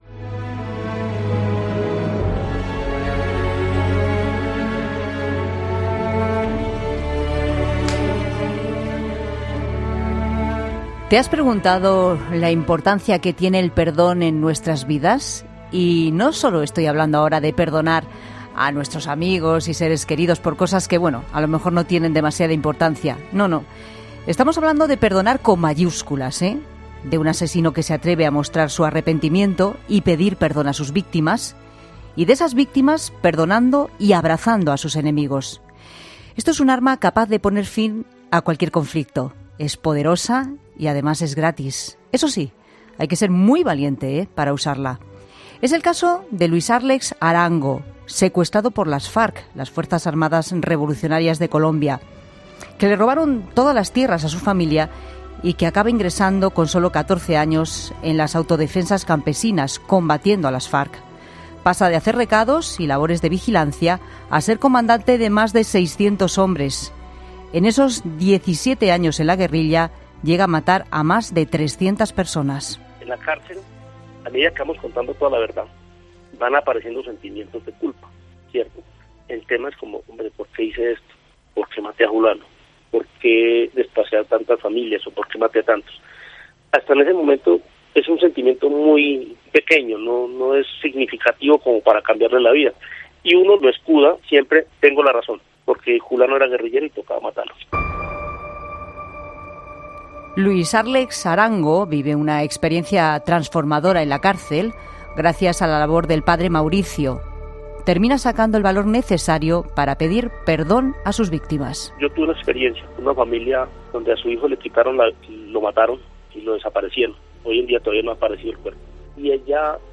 ESCUCHA LA ENTREVISTA COMPLETA EN 'LA TARDE' Irene ha reconocido en 'La Tarde' que ella se ha puesto también en el lugar del terrorista y del asesino.